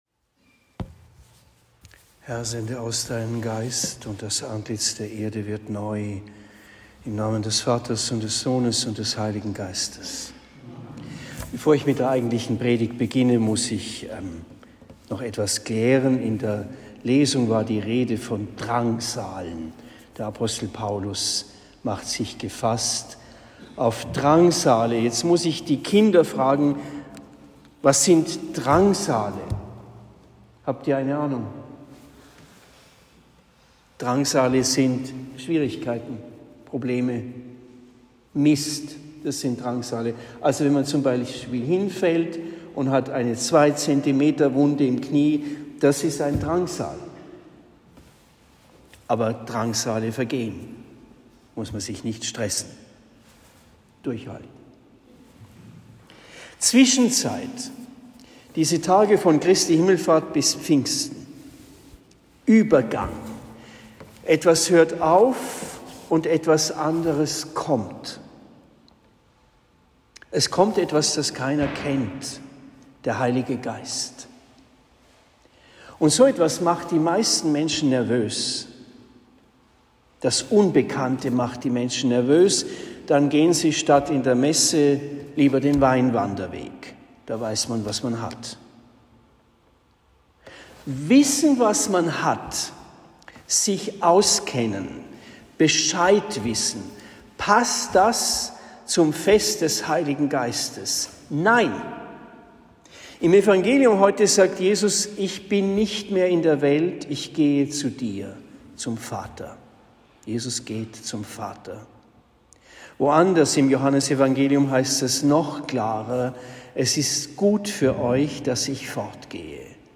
Predigt in Trennfeld am 23. Mai 2023